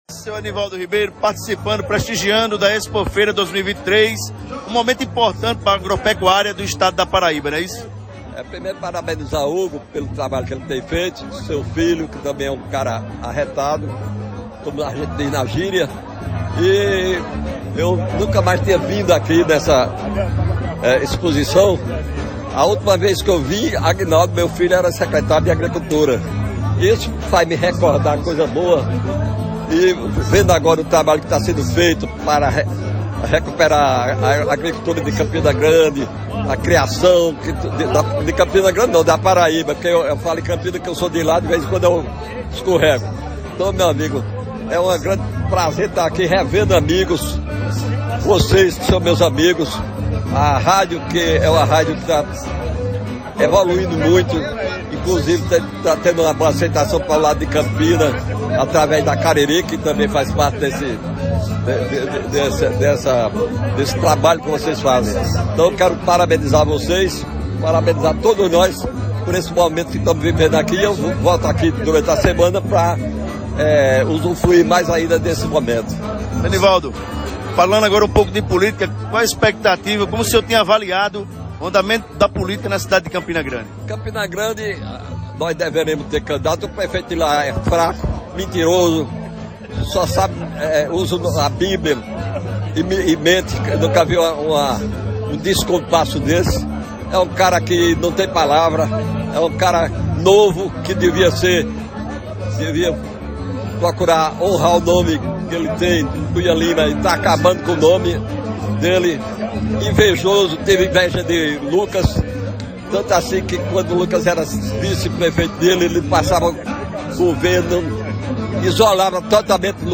O ex-deputado federal Enivaldo Ribeiro não poupou críticas à administração e ao prefeito Bruno Cunha Lima, do PSD, em Campina Grande, durante uma recente entrevista.